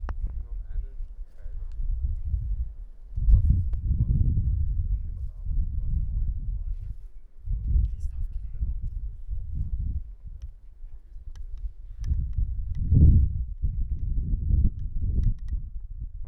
Die Tonqualität, sowie die Verständlichkeit der Dateien kann abweichend sein.
Während dieser Aufnahme befanden wir uns inmitten von Dreharbeiten. Im Hintergrund der Originalaufnahme hört man das Team mit der Reporterin reden.